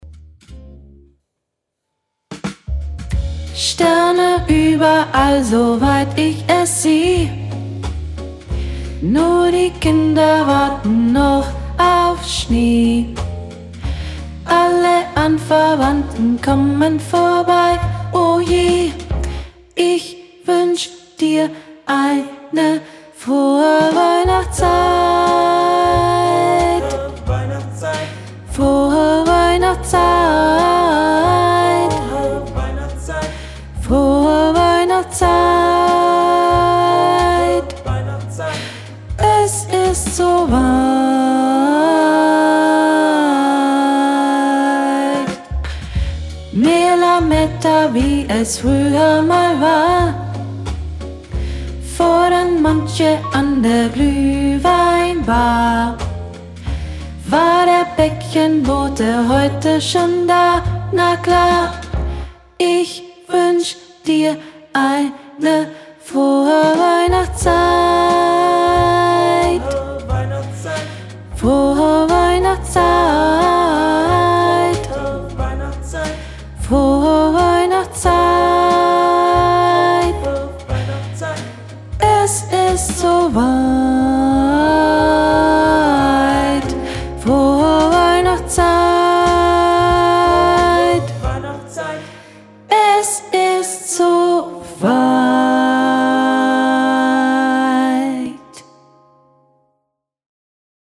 Übungsaufnahme Mezzo-Sopran
Frohe_Weihnachtszeit - Mezzo.mp3